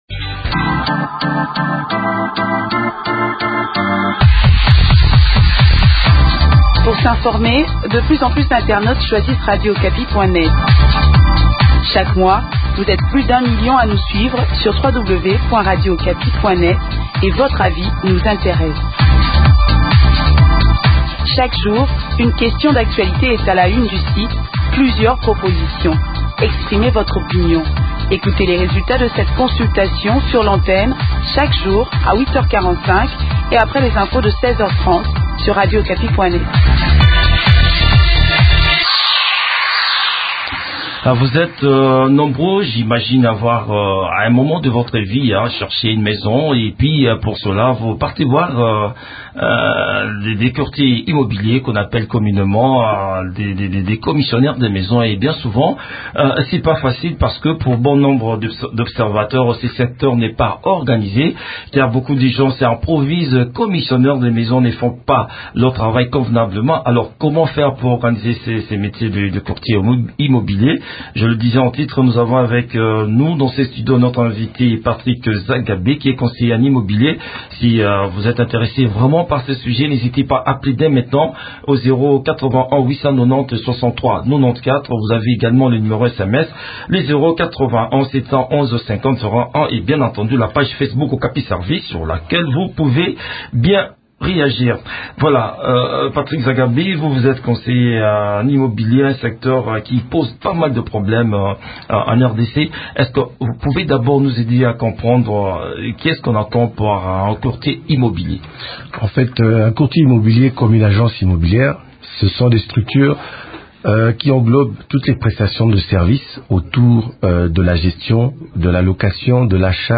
conseiller en immobilier.